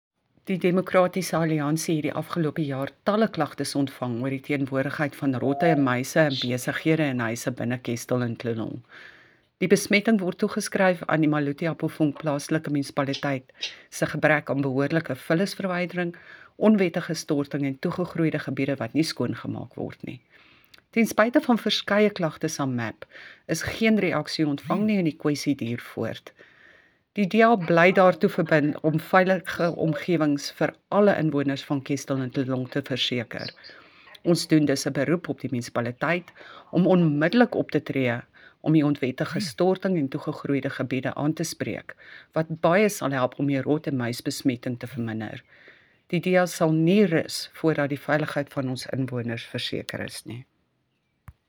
Afrikaans soundbites by Cllr Bea Campbell-Cloete and